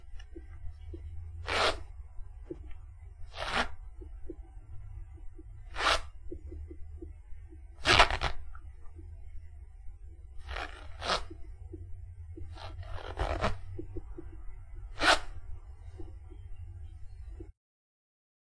Bathroom » zip2
描述：This is the sound of a zip opening and closing
标签： zip clothing bathroom
声道立体声